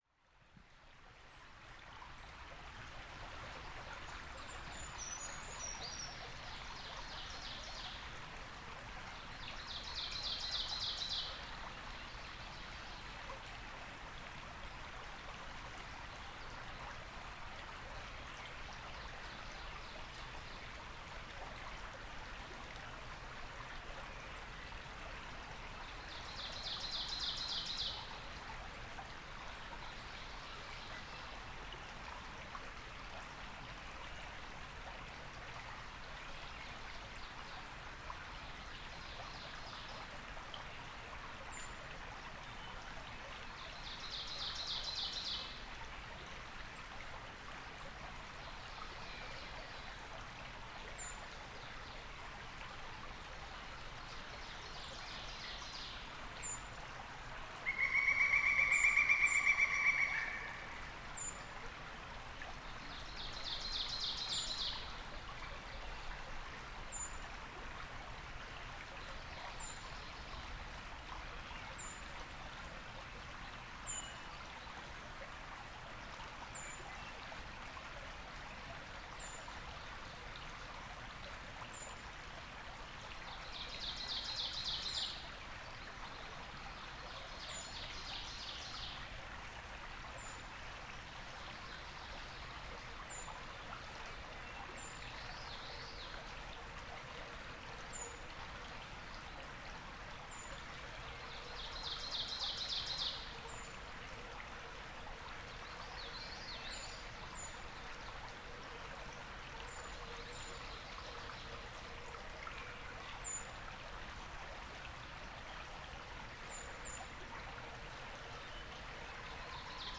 Enjoy this relaxing soundscape recorded along a stream in Shindagin Hollow.
this is a 3D binaural recording; please wear headphones!
I sat near a stream in a favorite spot and breathed easy for awhile, placing my soundscape microphone nearby just for good measure. The bird sounds were thin overall. An ovenbird sang off to one side and I could hear the high calls and occasional songs of a Brown Creeper. It was really quite mellow until a Pileated Woodpecker suddenly gave a laugh-like outburst of notes. About ten minutes later there was another unexpected sound event: a Red-shouldered Hawk landed on a tree not far downstream and gave a series of raucous calls.